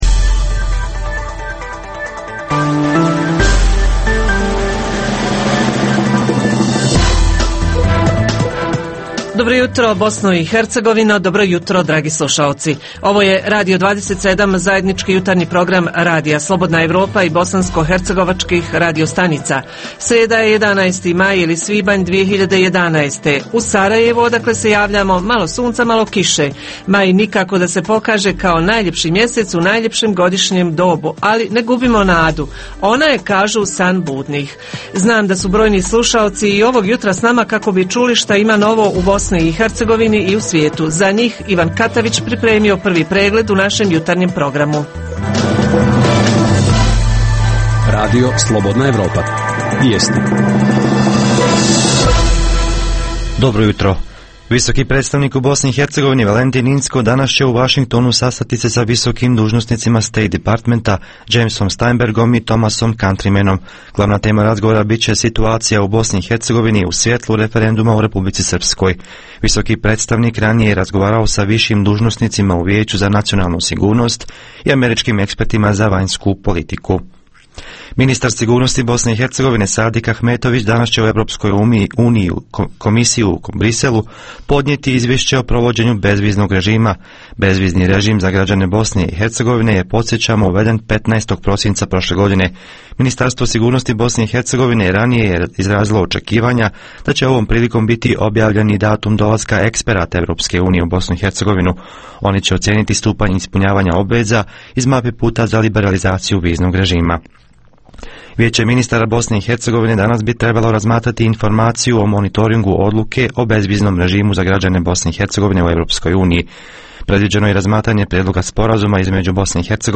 Proizvodnja aromatičnog i ljekovitog bilja, te uzgoj povrća u plastenicima - šansa ili ne? Mogu li se privući strani investitori? Reporteri iz cijele BiH javljaju o najaktuelnijim događajima u njihovim sredinama.